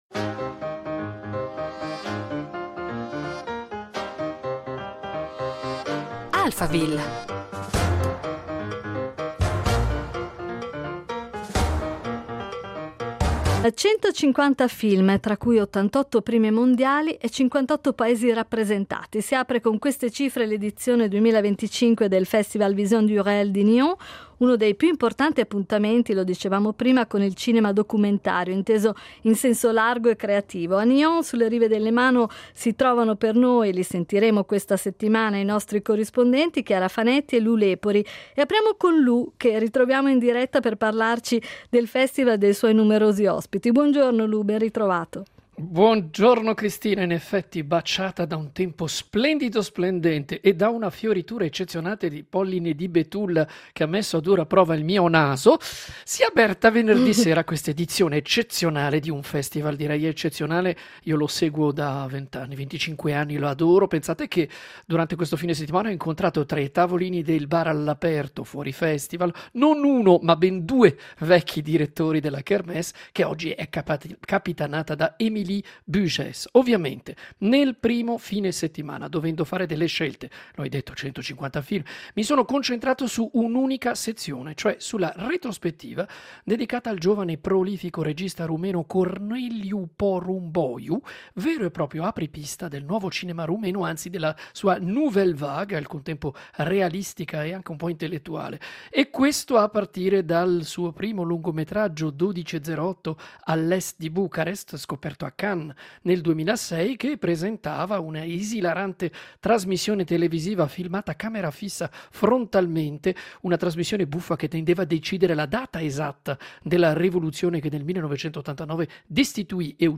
ha intervistato Corneliu Porumboiu, cineasta rumeno a cui il Festival dedica una prima retrospettiva: con i sette film finora realizzati dal cineasta quarantanovenne.